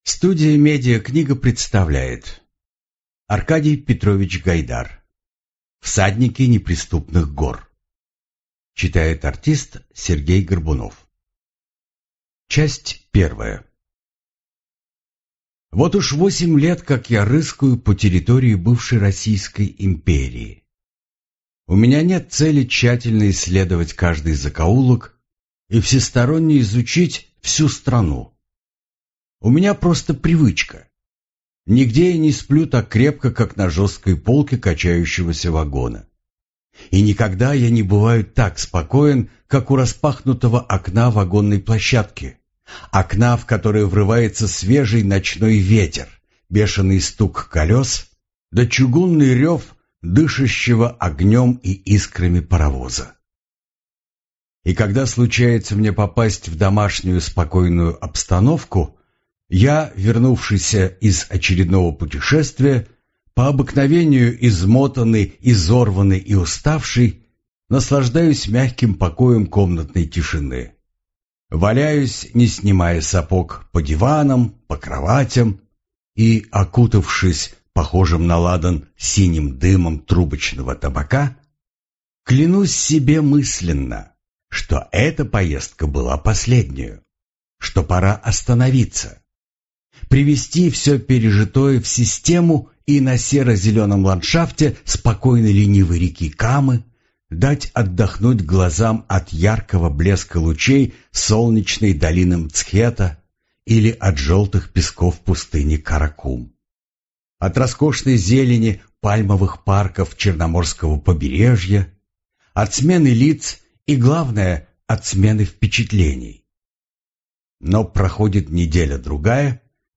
Аудиокнига Всадники неприступных гор | Библиотека аудиокниг